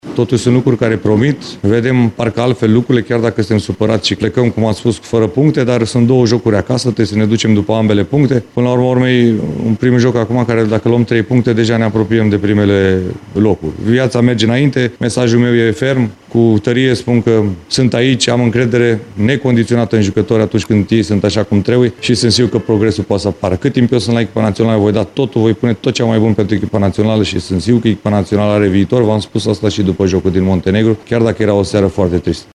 După meci, selecționerul Edward Iordănescu s-a declarat mulțumit de atitudinea elevilor săi de la Zenica și a spus că rămâne optimis și că echipa națională are viitor: